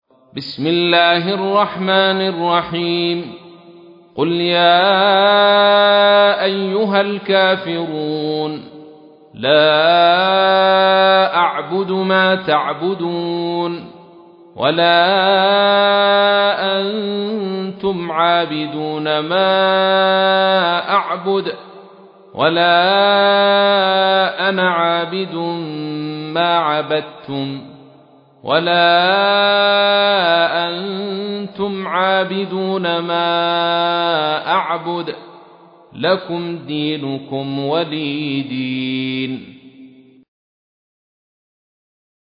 تحميل : 109. سورة الكافرون / القارئ عبد الرشيد صوفي / القرآن الكريم / موقع يا حسين